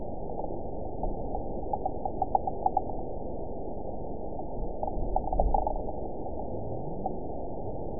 event 912615 date 03/30/22 time 12:21:37 GMT (3 years, 1 month ago) score 9.40 location TSS-AB05 detected by nrw target species NRW annotations +NRW Spectrogram: Frequency (kHz) vs. Time (s) audio not available .wav